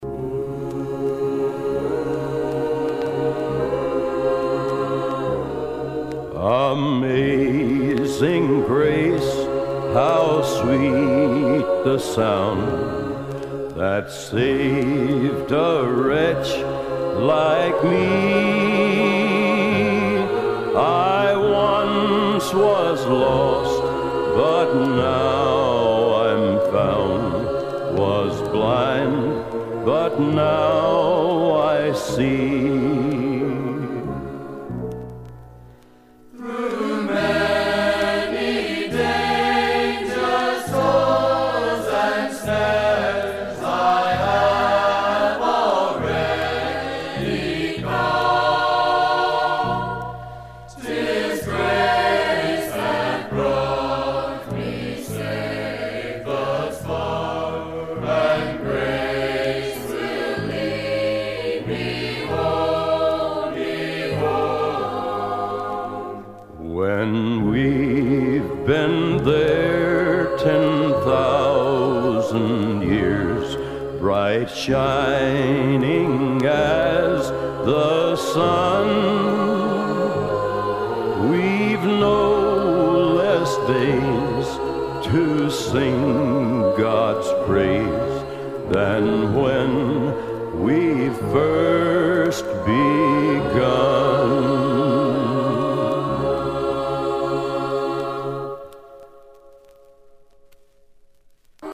Side two was with school chorus.